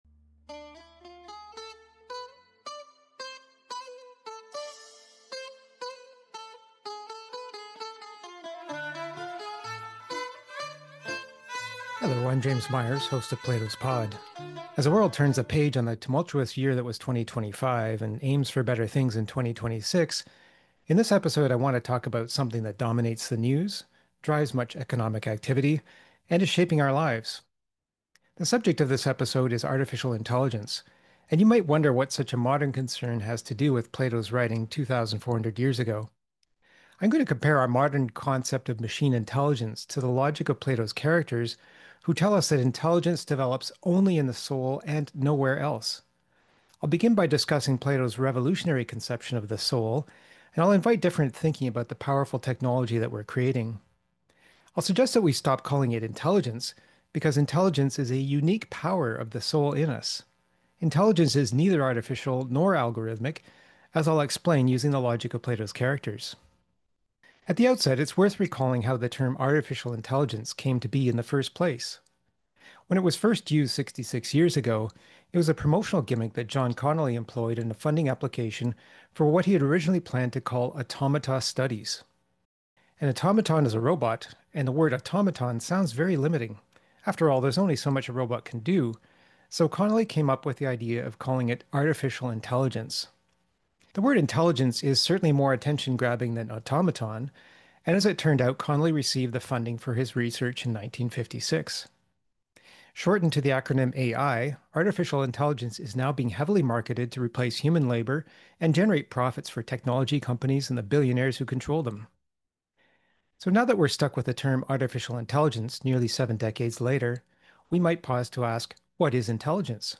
Episodes are lightly edited for clarity, with care to avoid compromising the contributions made by participants.